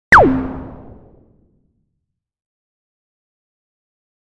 SE（SF銃）
SFの銃。ぴゅーん。ピューン。